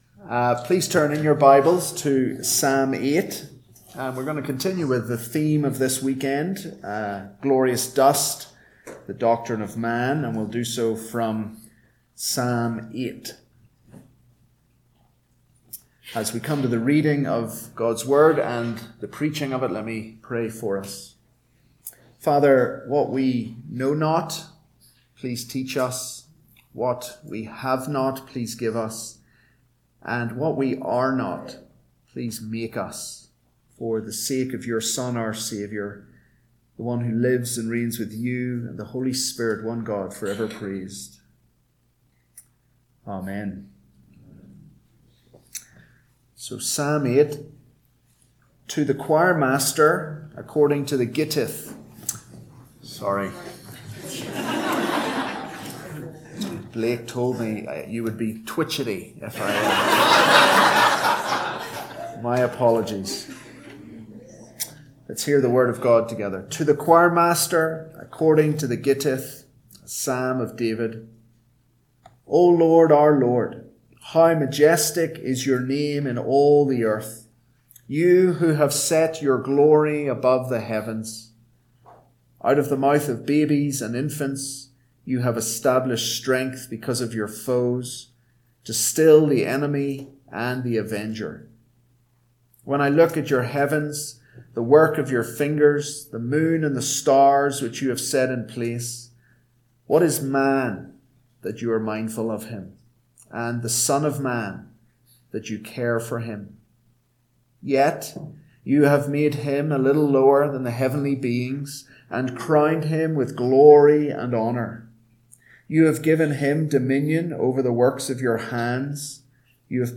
AM Sermon – 9/29/2024 – Psalm 8 – Northwoods Sermons
The Worship Service of the 2024 Cheyenne Reformation Conference.